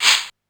Index of /musicradar/essential-drumkit-samples/DX:DMX Kit
DX Shaker 02.wav